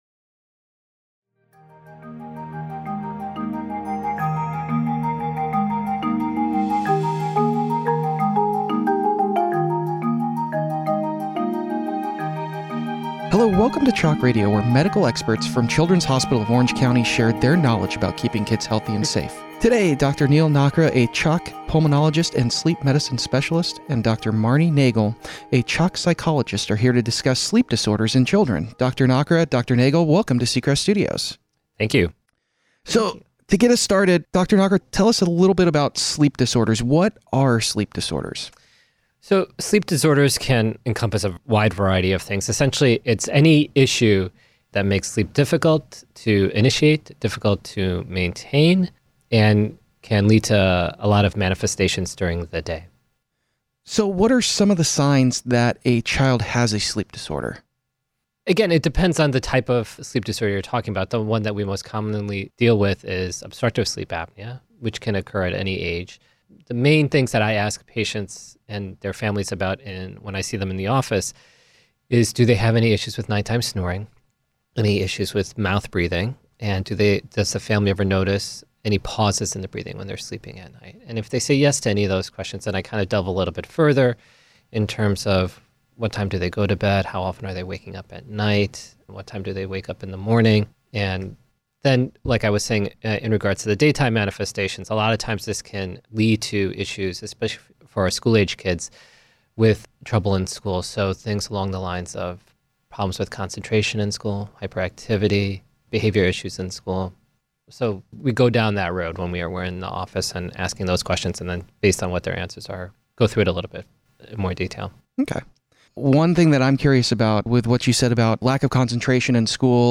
A CHOC pediatric sleep specialist and a pediatric psychologist discuss medical and behavior sleep problems, and how sleep affects health.||A CHOC pediatric sleep specialist and a pediatric psychologist discuss medical and behavior sleep problems, and how sleep affects health.